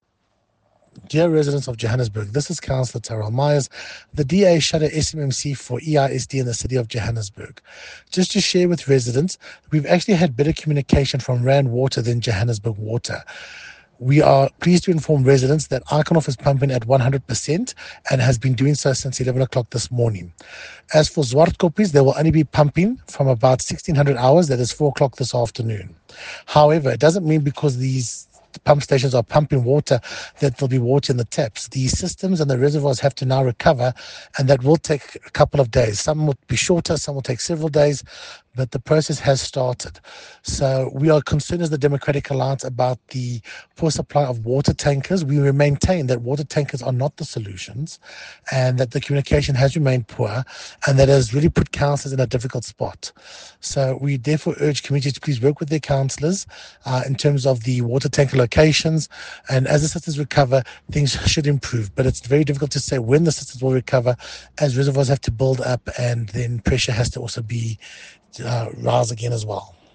Note to Editors: Please find an English soundbite by Cllr Tyrell Meyers